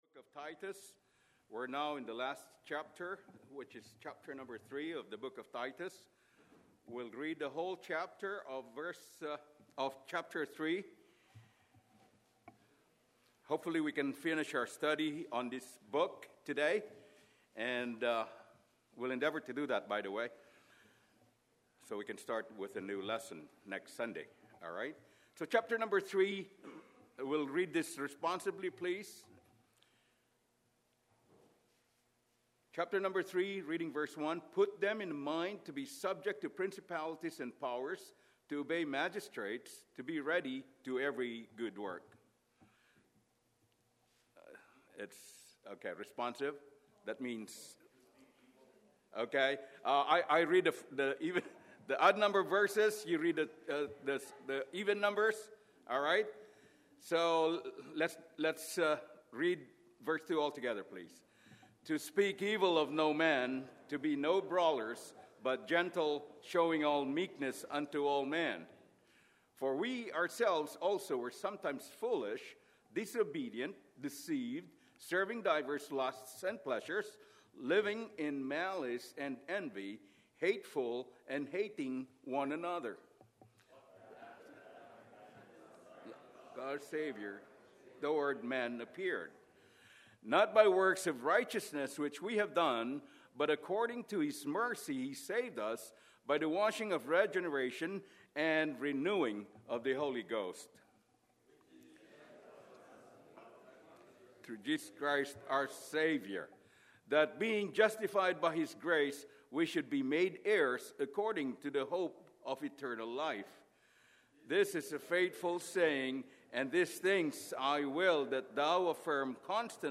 Sermons | Anchor Baptist Church